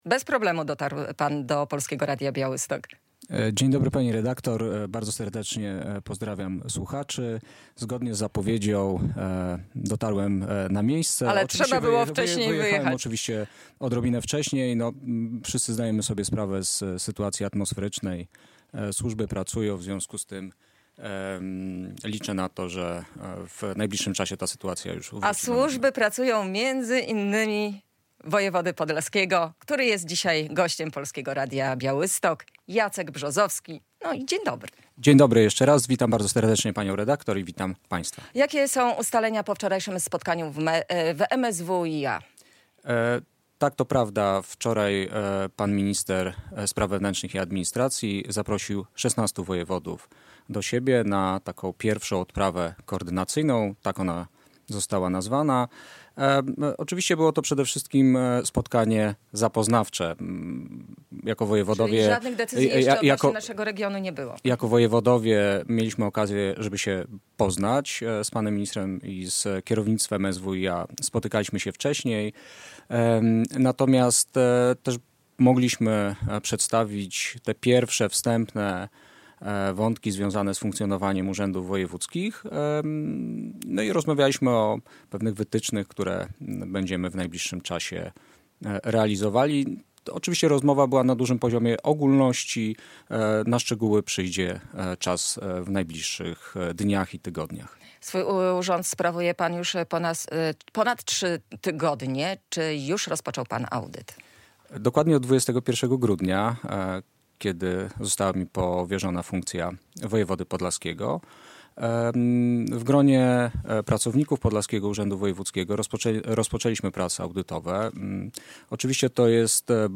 Pushbacki na razie nie są wstrzymane, ale trwają prace, by to zmienić - mówił w Polskim Radiu Białystok wojewoda podlaski Jacek Brzozowski.
Radio Białystok | Gość | Jacek Brzozowski [wideo] - wojewoda podlaski